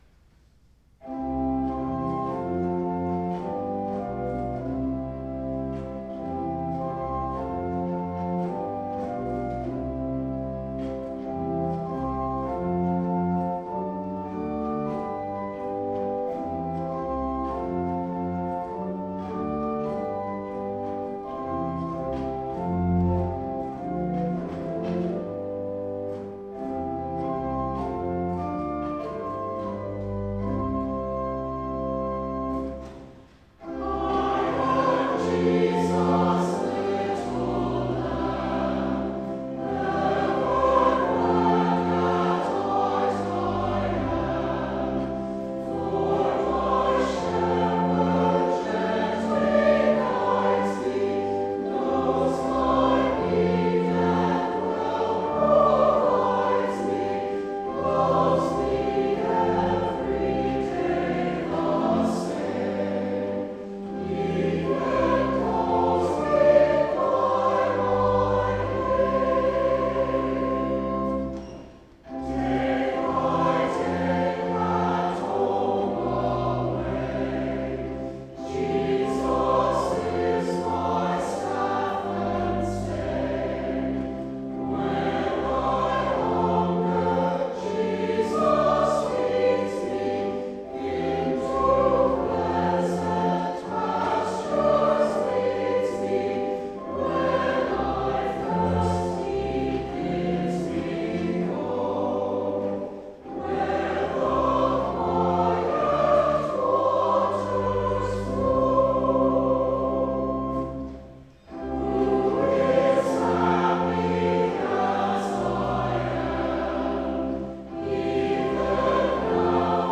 1787 Tannenberg Organ
The following three hymns were recorded in May of 2010 with the Lititz Moravian Church Senior Choir:
Hymn-I_Am_Jesus_Little_Lamb.wav